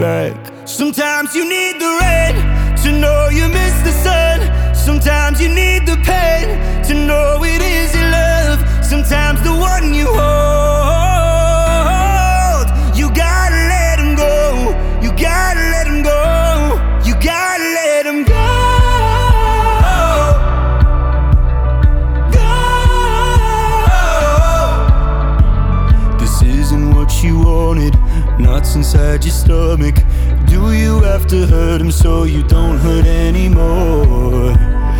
Жанр: Поп / Альтернатива